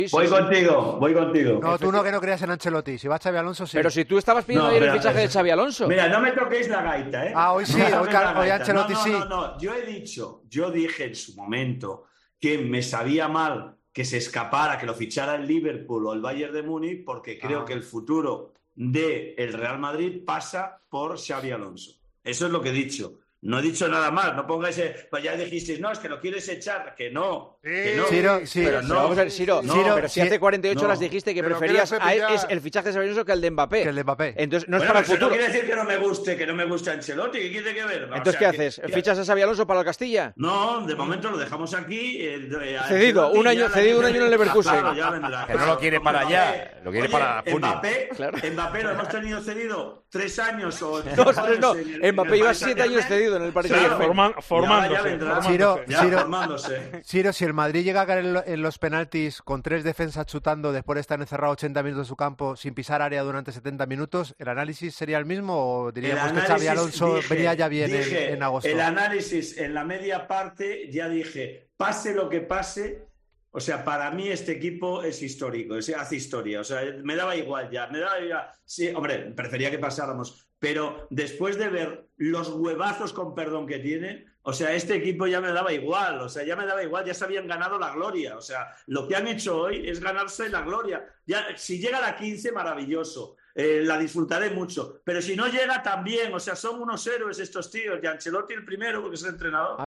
El comentarista de El Partidazo de COPE mostró su felicidad tras la clasificación del Real Madrid para semifinales pero los compañeros le recordaron qué dijo sobre Ancelotti.